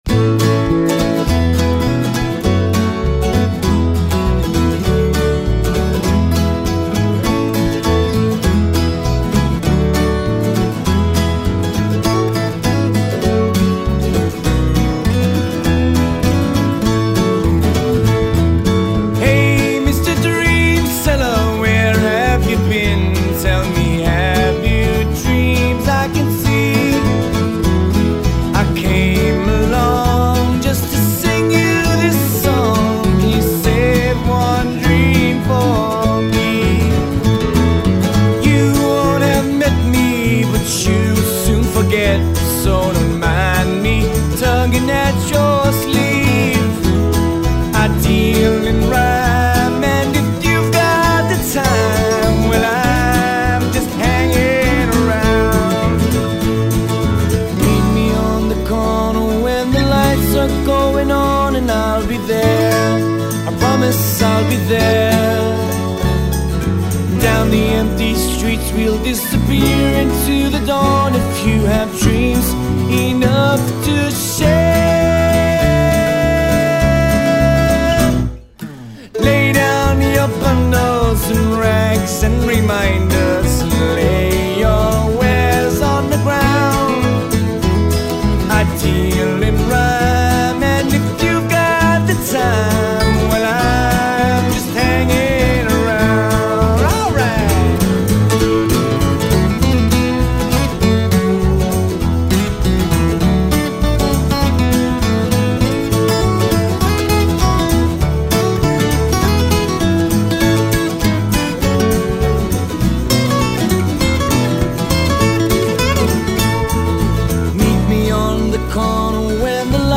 vocals
piano, bass & guitars
guitars, mandolin, percussion & vocals.